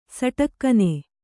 ♪ saṭakkane